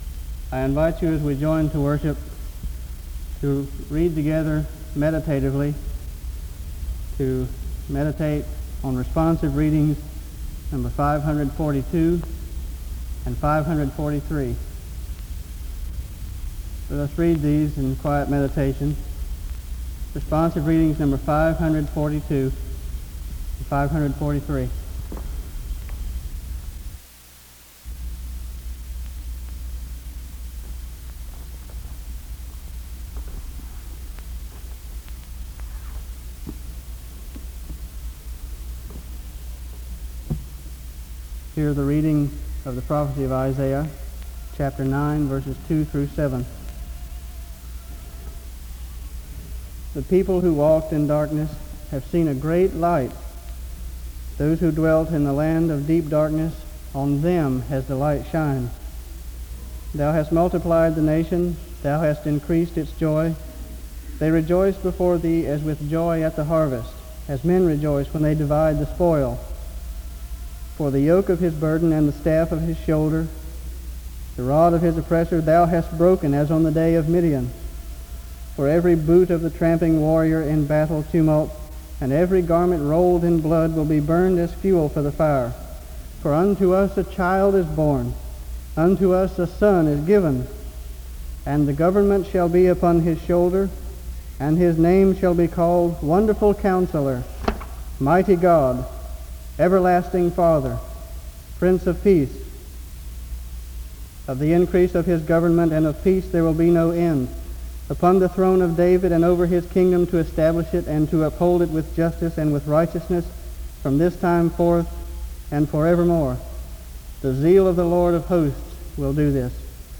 The service begins with a responsive reading from 0:00-0:40. Isaiah 9:2-7 is read from 0:41-2:00.
A moment of silent prayer takes place from 2:05-3:54.